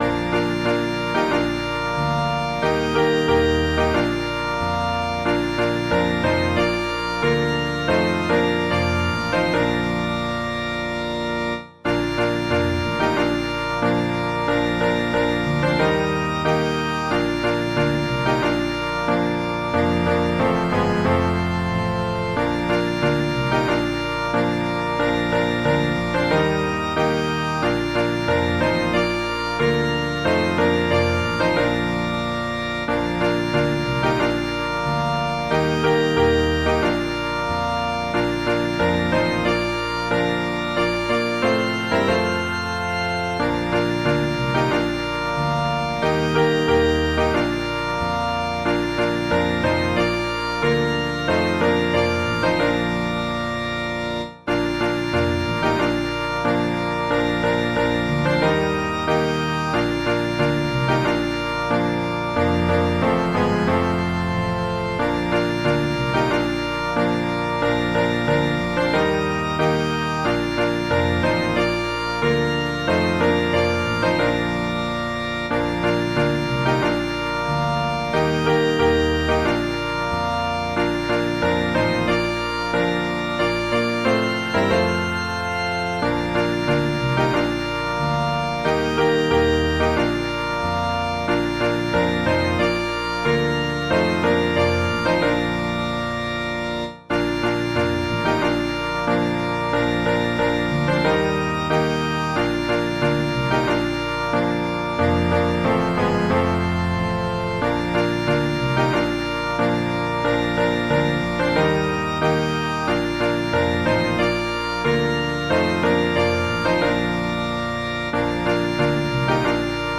Instrumental accompaniment